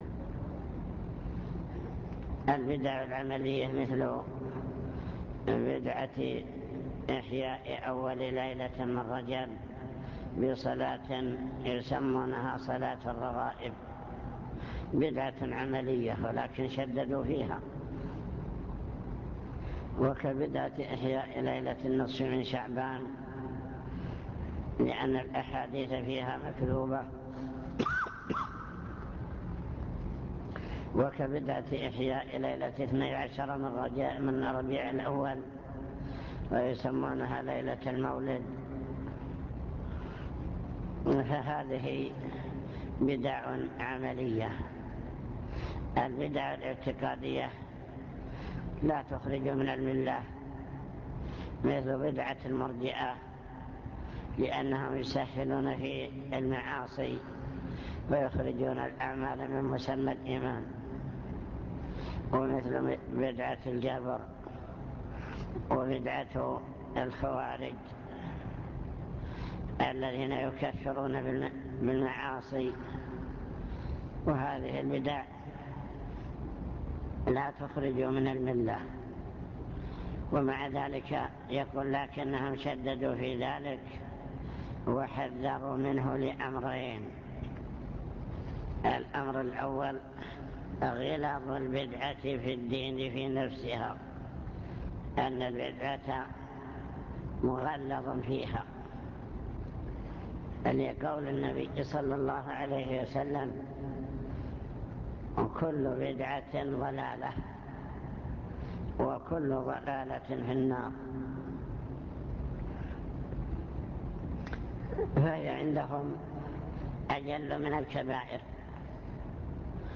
المكتبة الصوتية  تسجيلات - كتب  شرح كتاب مفيد المستفيد في كفر تارك التوحيد